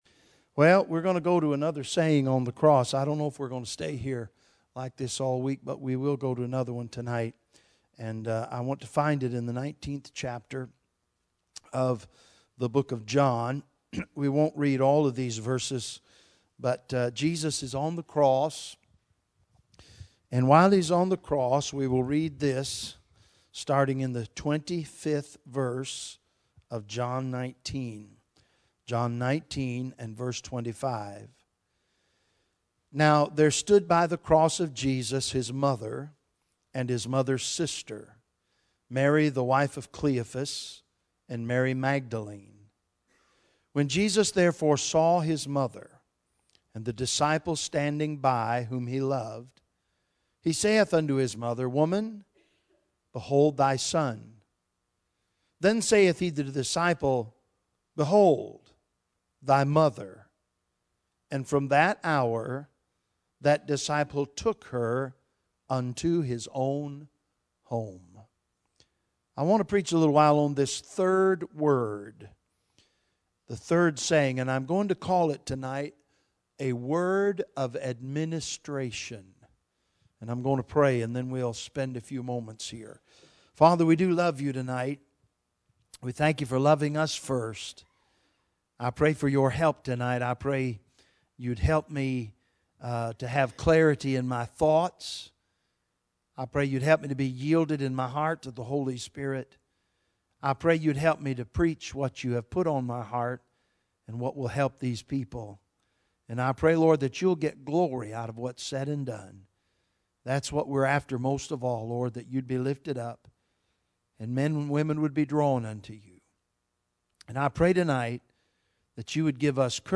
Bible Text: John 19:25 | Preacher